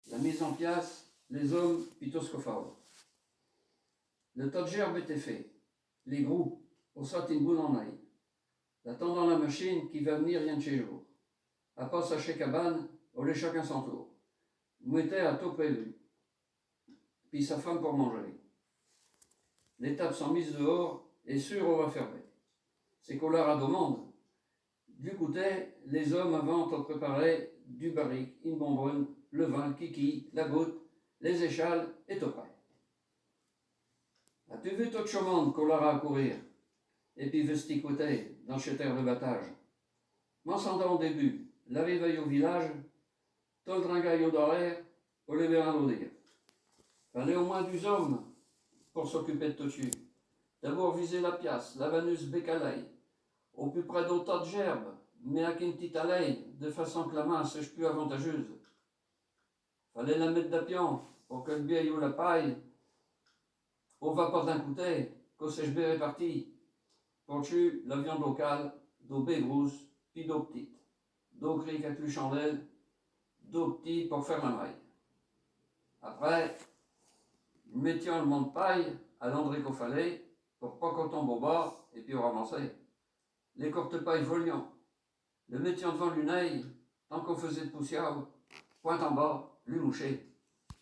Genre poésie
Poésies en patois
Catégorie Récit